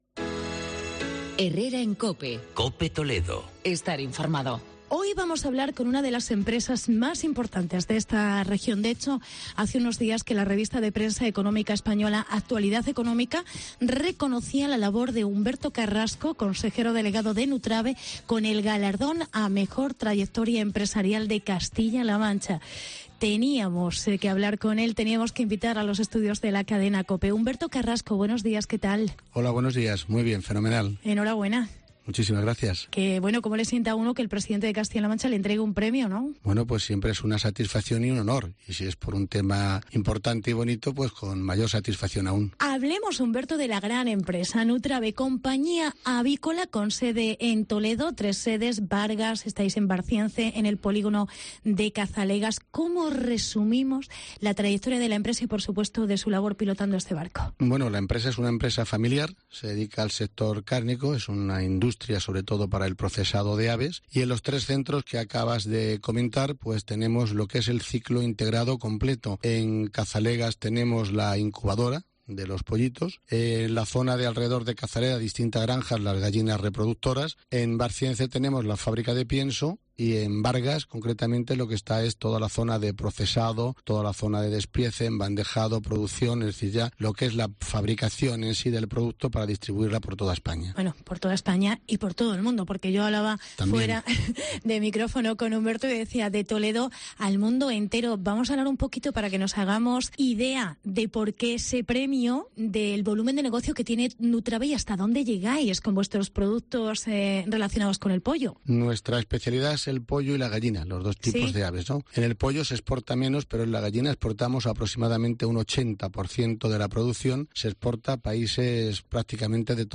La empresa toledana Nutrave entre las seis primeras de España. Entrevista